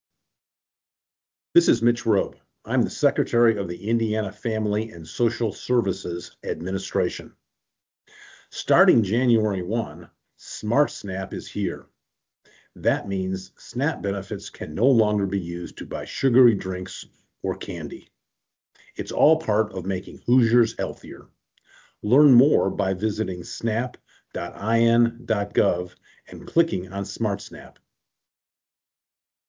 FSSA Secretary Mitch Roob's Smart SNAP Public Service Announcement